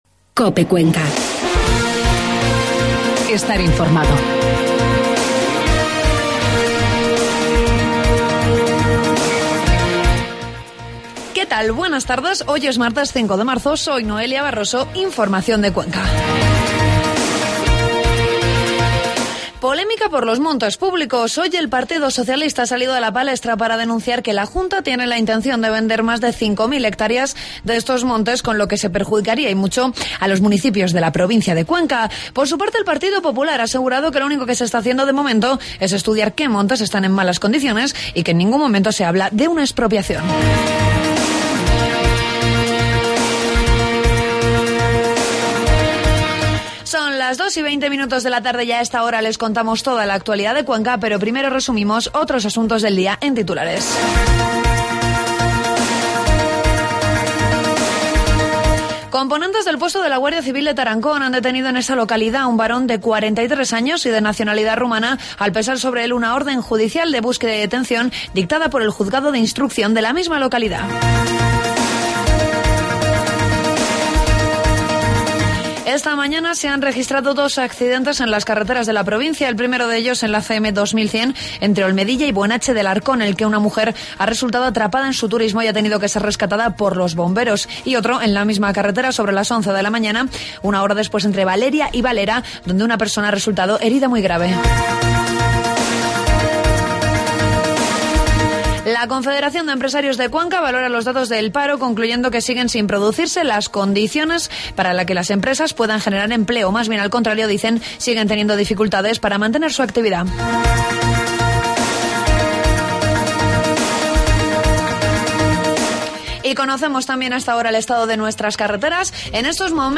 Toda la información de la provincia de cuenca en los informativos de mediosía de COPE